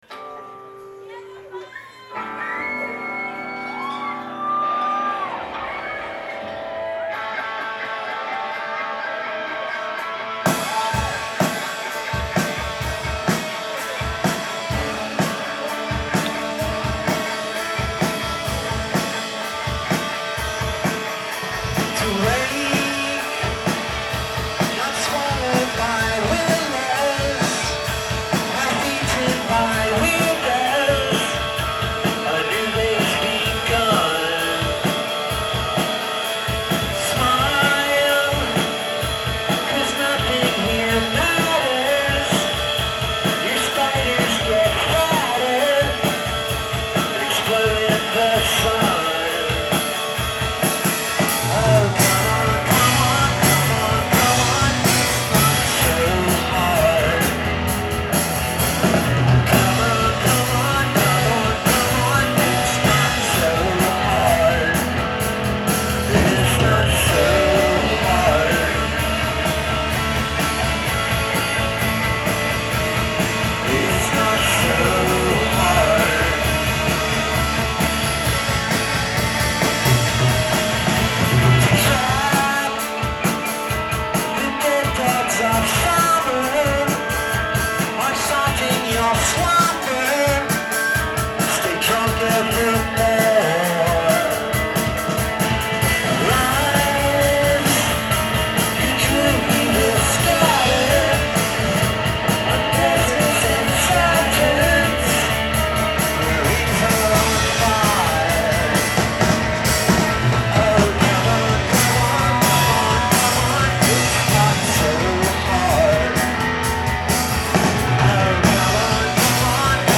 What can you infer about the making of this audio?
Live at The Paradise in Boston, Massaschuetts